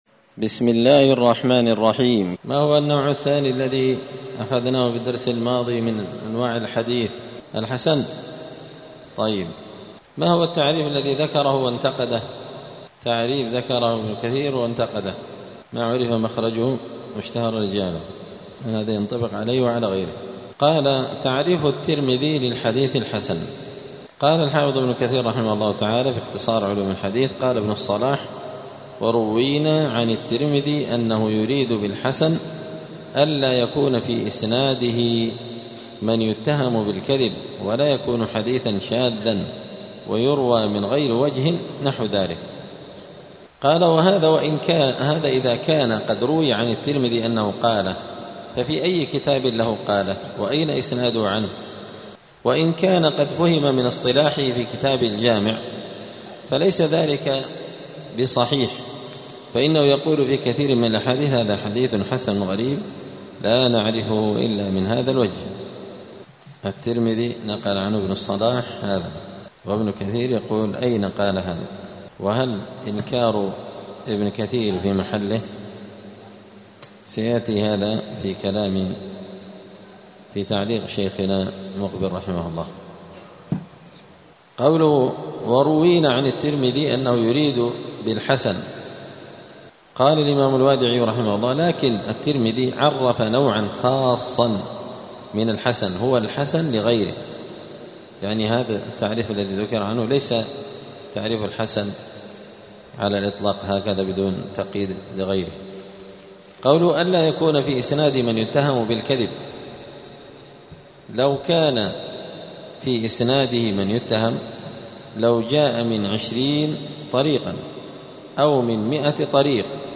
السير الحثيث شرح اختصار علوم الحديث – الدرس السابع عشر (17) : تعريف الترمذي للحديث الحسن.
دار الحديث السلفية بمسجد الفرقان قشن المهرة اليمن